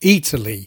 This means that when Italians say Italy, it can sound to English speakers like ‘Eataly’.